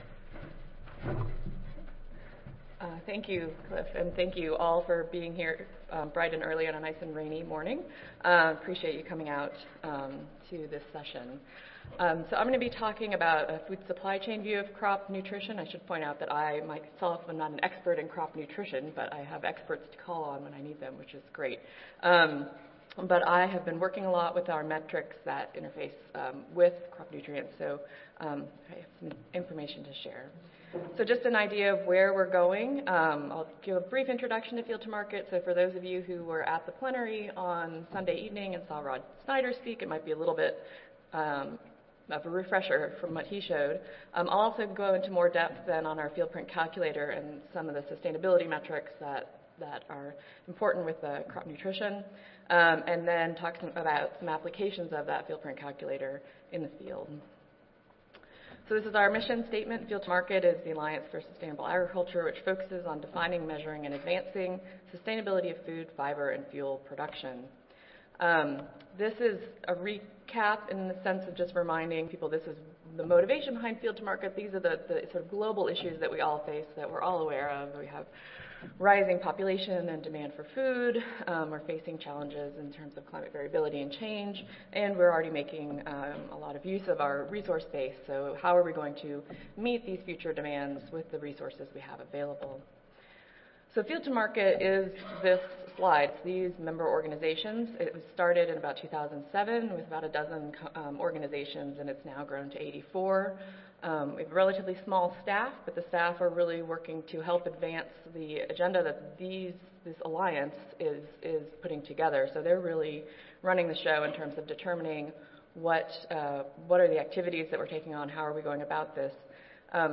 Recorded Presentation